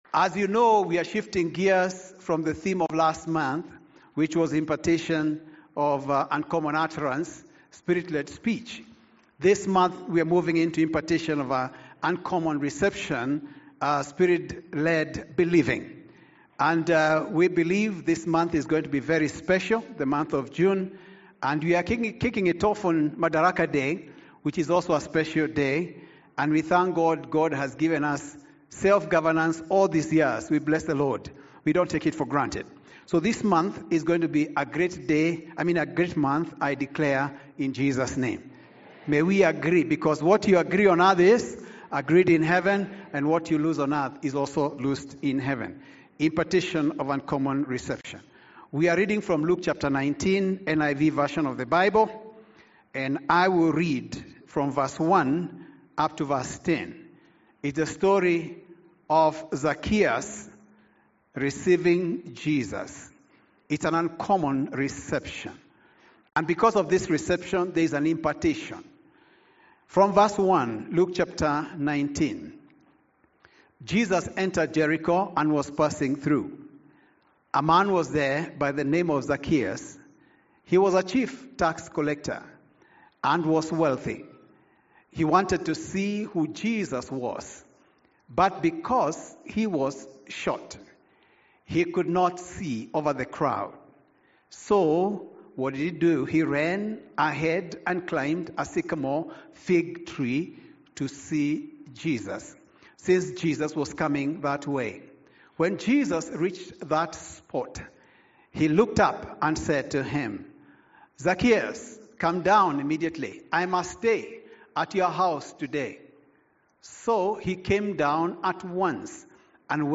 Sermons
01st-June-Sunday-Service-1.mp3